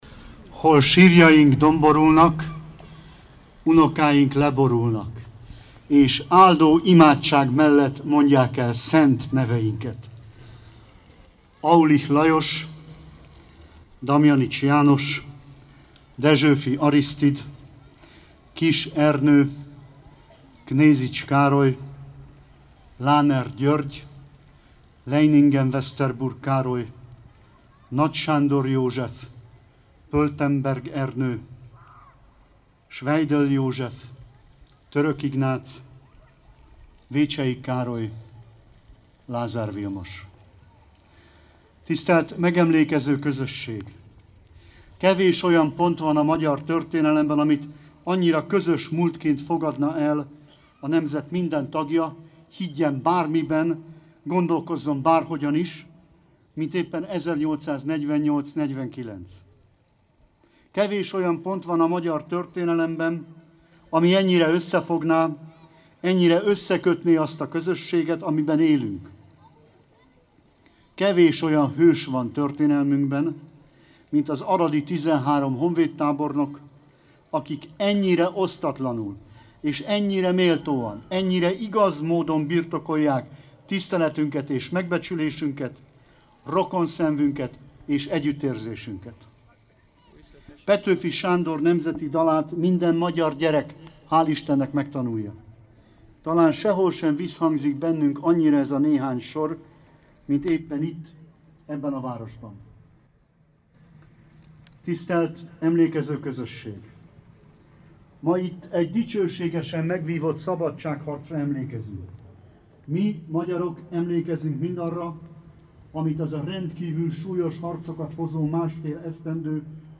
Senki sem lehet szabad, ha a mellette élő nem szabad – jelentette ki Aradon, a 13 vértanú tábornok emlékére állított Szabadság-szobor melletti megemlékezésen Hende Csaba magyar honvédelmi miniszter. Szerinte a vértanúkra való emlékezés a magyar-román összefogás akkor elszalasztott lehetőségére is emlékeztet.
Hende_Csaba_beszede.mp3